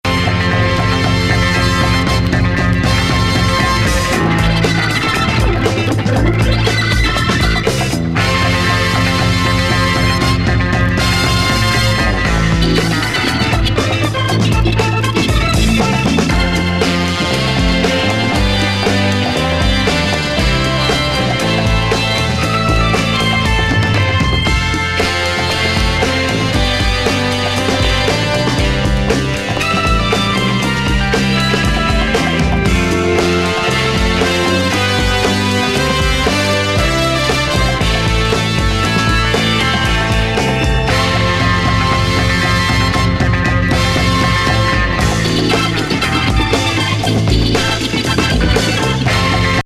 血沸き肉踊るボンゴ乱れ打ち